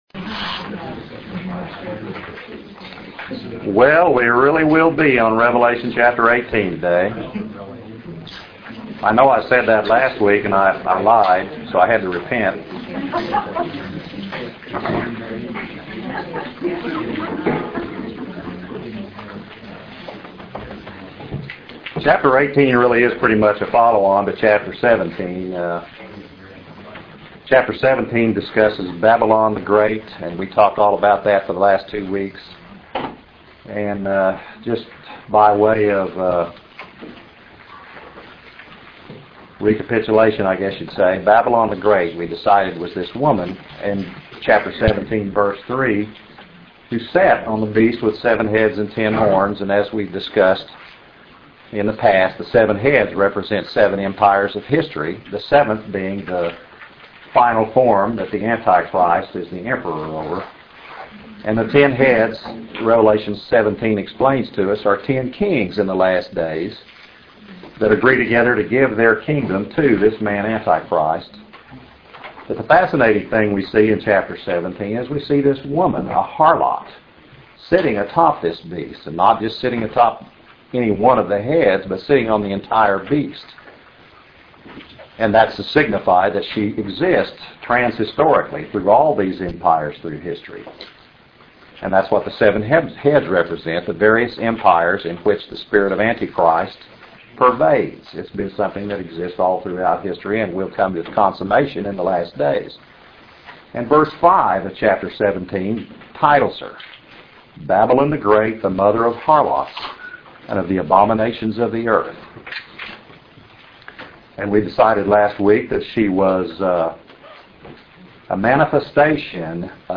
Book of Revelation - a Verse by Verse Audio Study - Chapter 18 pt 1 - Judgment of Babylon the Great